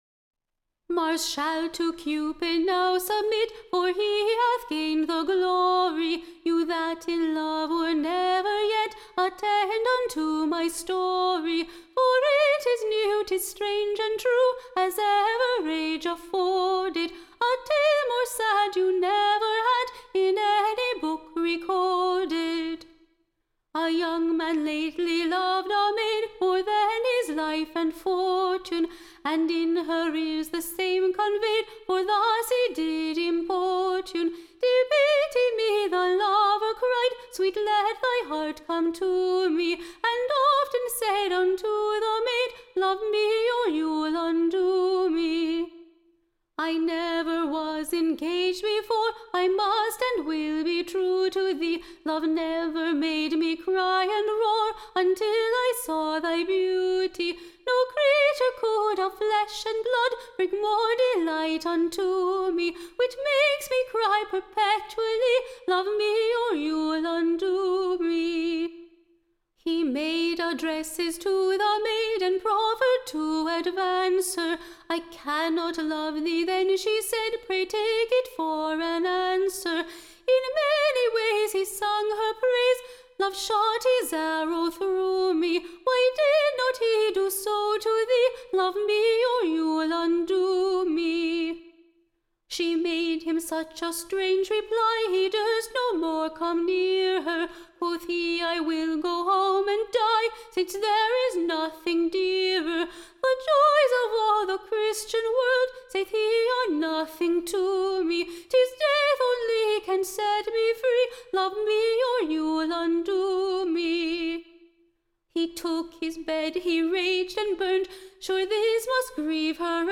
Recording Information Ballad Title The Ruined Lovers.
Tune Imprint To the Tune of, Mock-Beggers Hall stands empty.